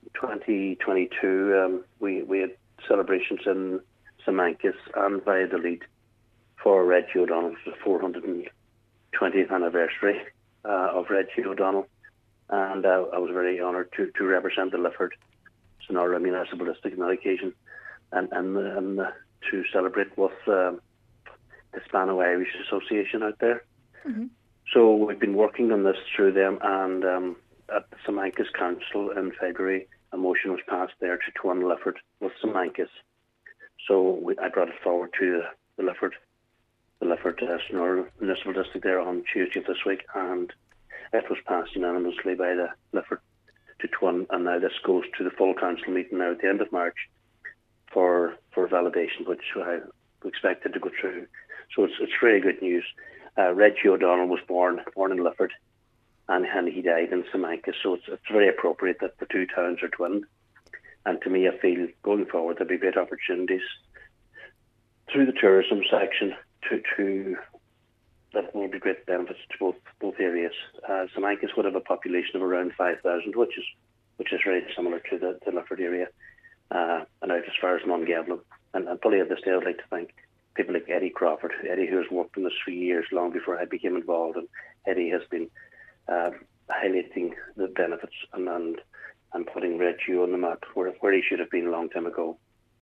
Cathaoirleach of Donegal County Council, Councillor Martin Harley says the initiative would afford the MD great opportunities: